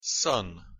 Pronunciation En Son (audio/mpeg)